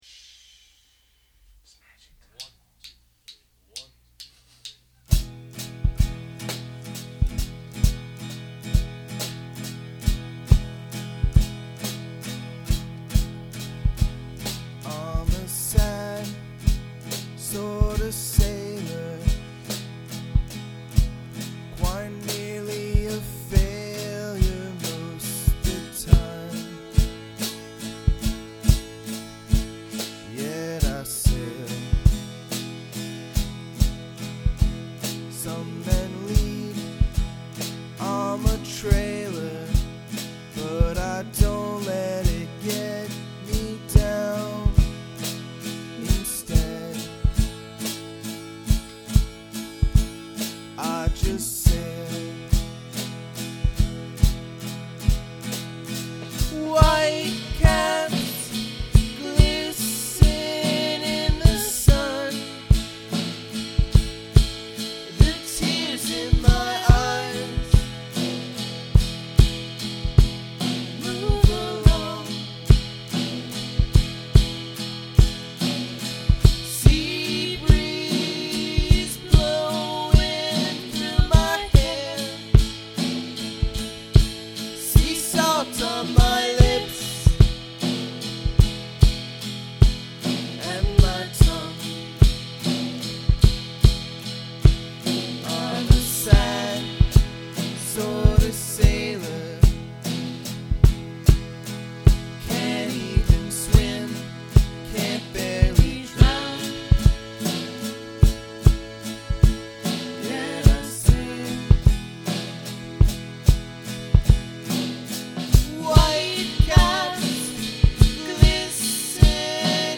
guitar, vocals